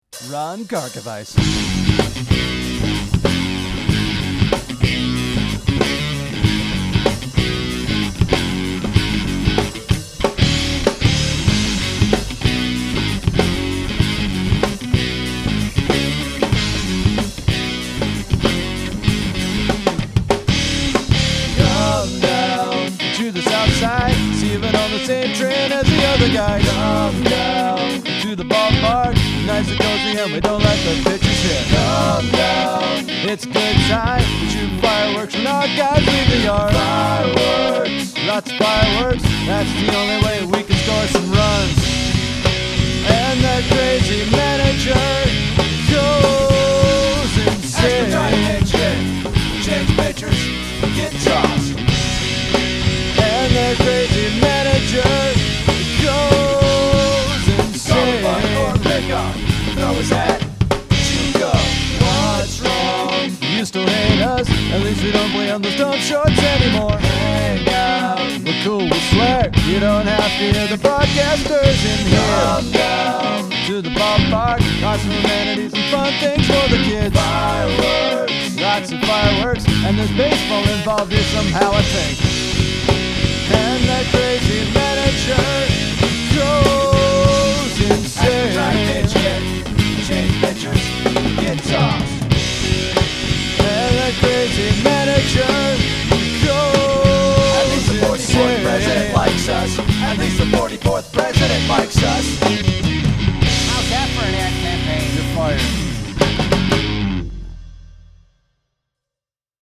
Musically, I opted for a shuffle groove mainly for a change of pace. I further accented this change with what I like to call Gratuitous Use Of The 5-String Bass.
Also: While I was writing the lyrics, I noticed that it sounded like a bad ad campaign, so that’s why the talking part at the very end was thrown in.